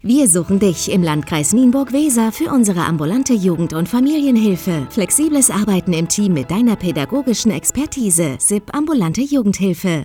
Radio Kampagne: